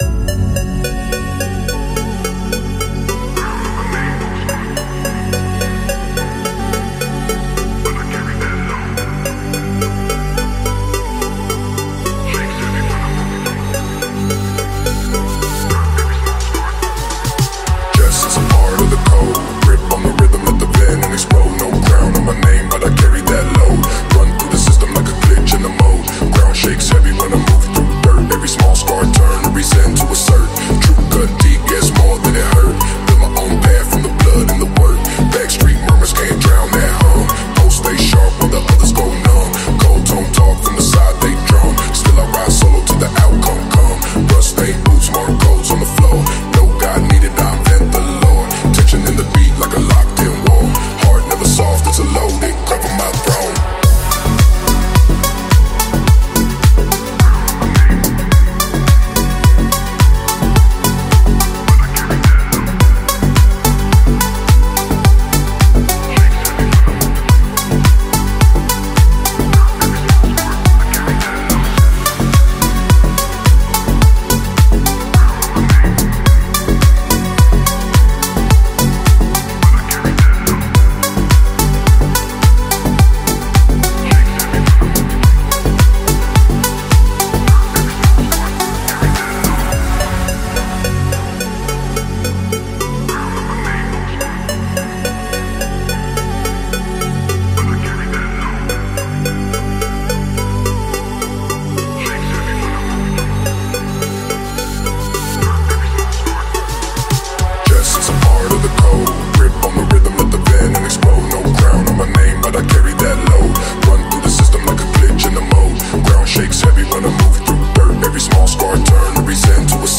با ریتم‌های کوبنده و اتمسفر سینمایی
Deep، Epic، Dark و Cinematic